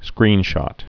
(skrēnshŏt)